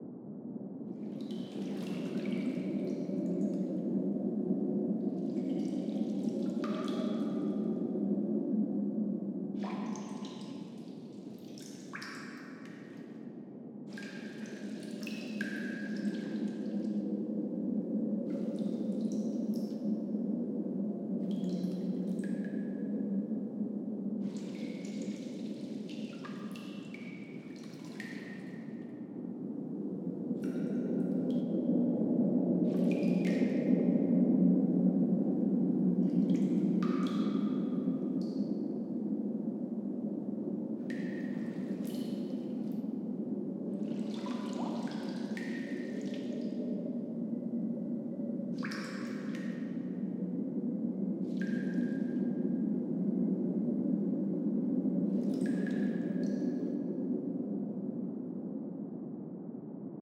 Free Fantasy SFX Pack
BGS Loops
Cave.ogg